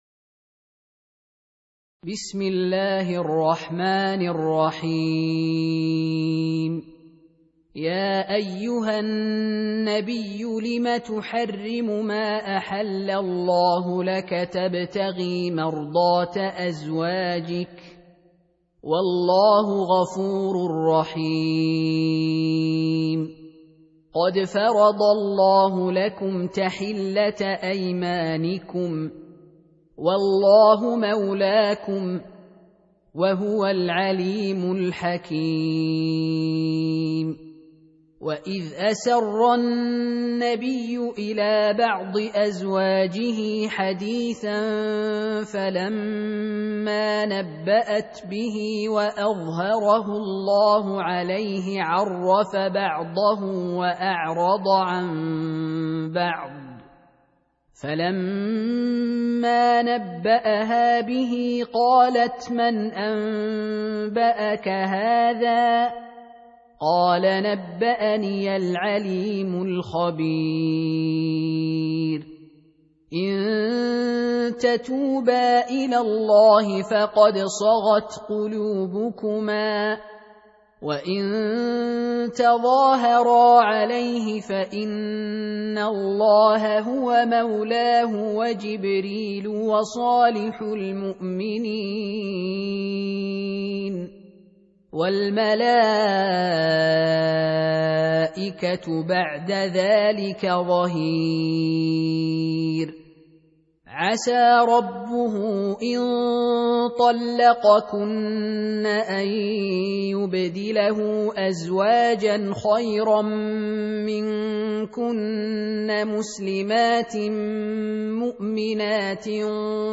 Surah Repeating تكرار السورة Download Surah حمّل السورة Reciting Murattalah Audio for 66. Surah At-Tahr�m سورة التحريم N.B *Surah Includes Al-Basmalah Reciters Sequents تتابع التلاوات Reciters Repeats تكرار التلاوات